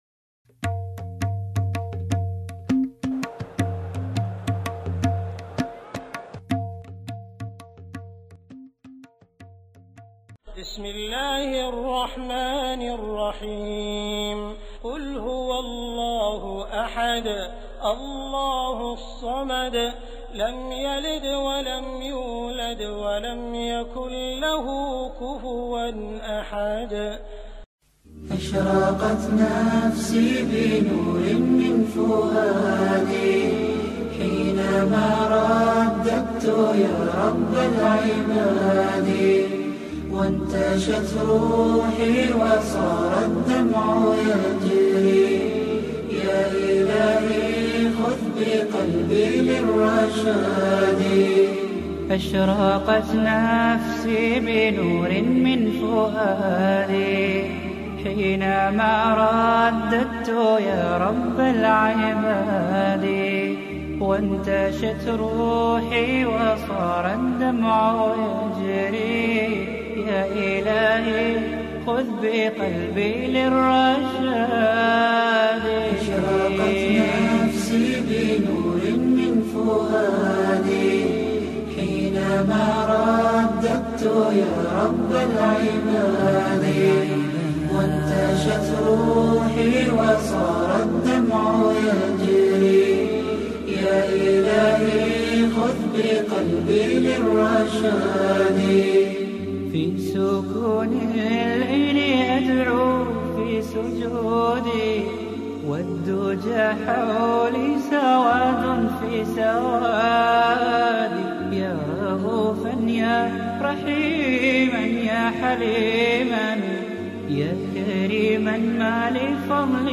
Translation of Nasheed